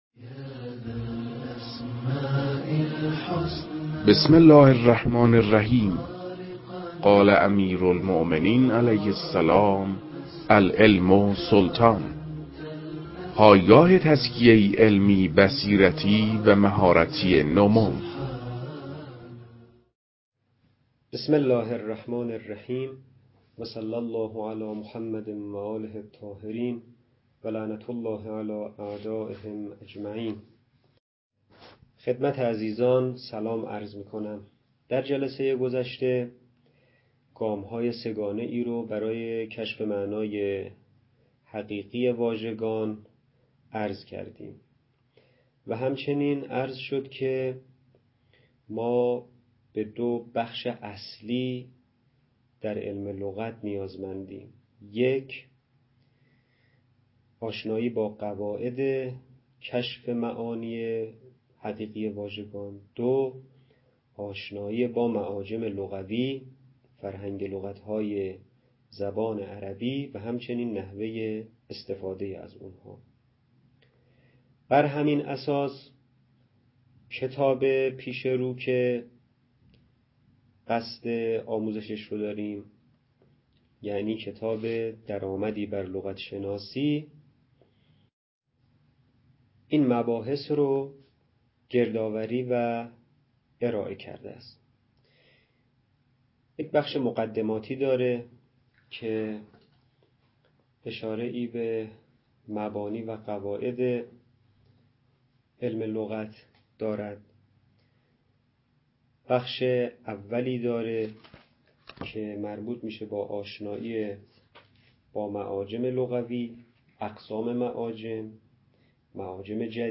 در این بخش، کتاب «درآمدی بر لغت‌شناسی» که اولین کتاب در مرحلۀ آشنایی با علم لغت است، به صورت ترتیب مباحث کتاب، تدریس می‌شود.
در تدریس این کتاب- با توجه به سطح آشنایی کتاب- سعی شده است، مطالب به صورت روان و در حد آشنایی ارائه شود.